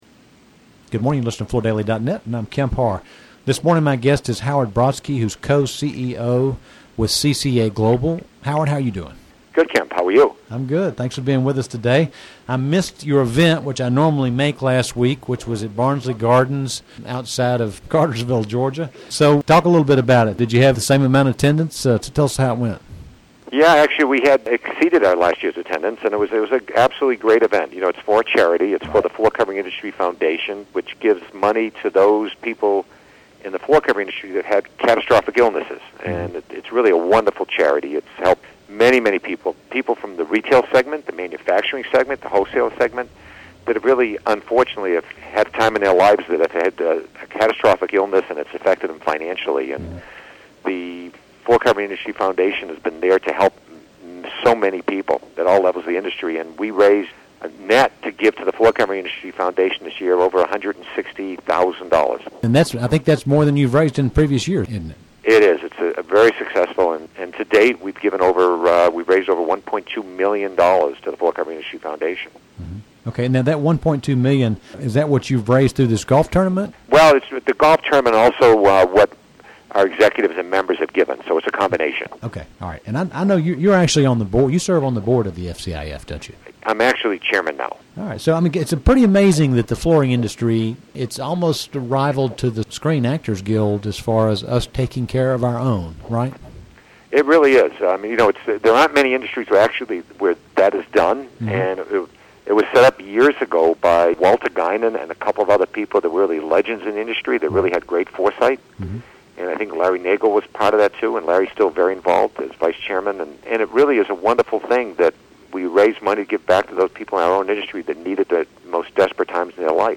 Listen to the interview to hear more about this event, the FCIF and about current market conditions with CCA's members.